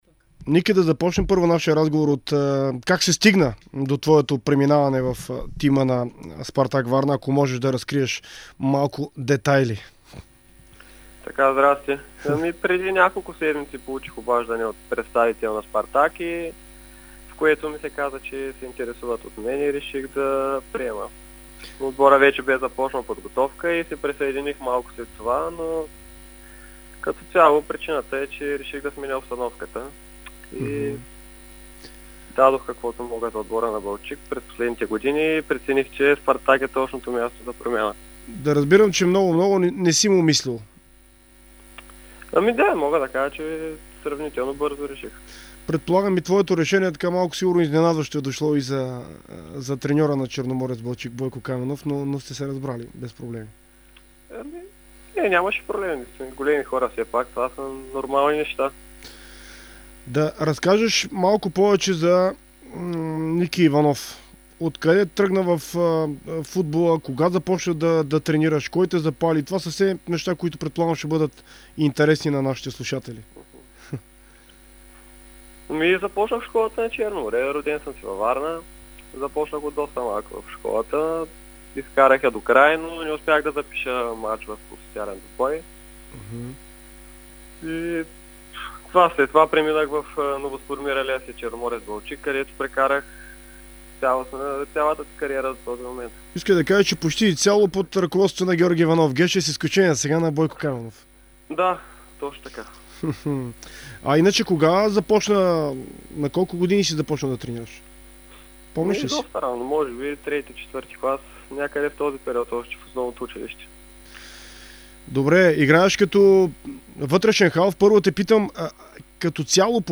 интервю за Дарик радио и dsport